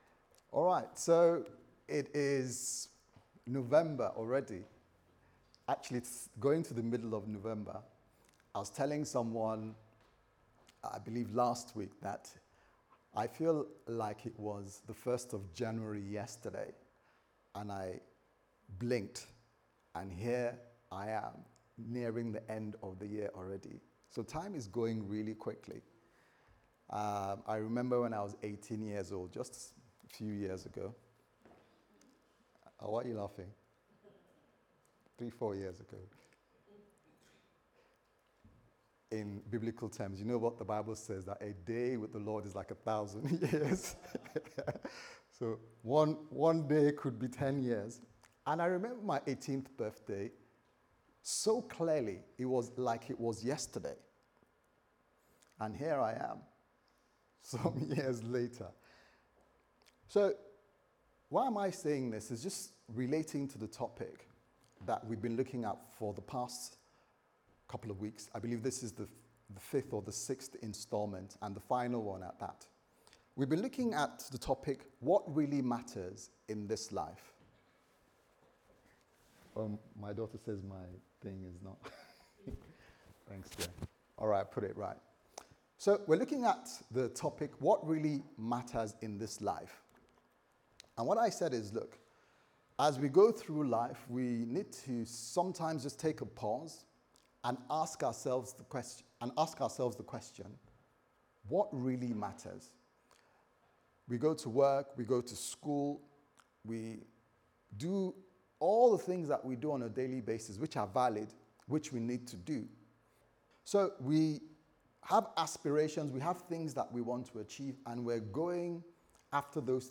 What Really Matters Service Type: Sunday Service Sermon « What Really Matters In This Life